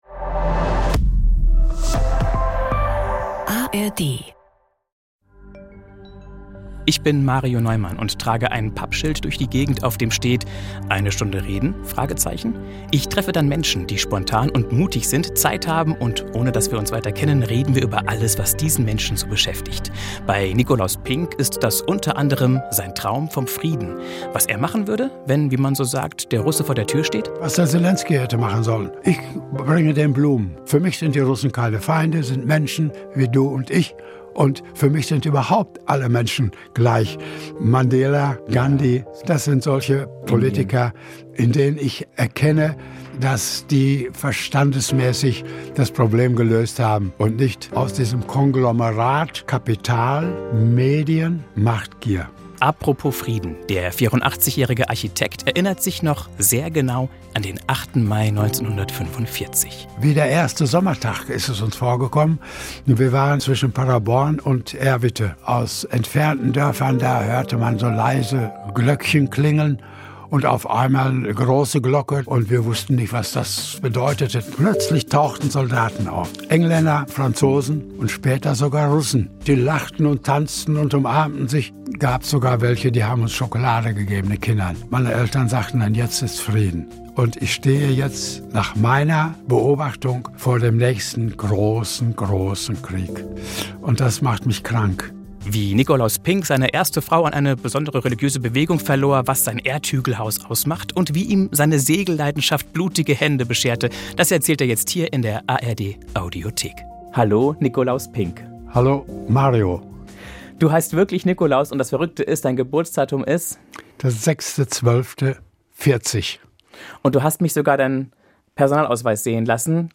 Er wäre gerne Friedensstifter ~ Eine Stunde reden – Gespräche mit Unbekannten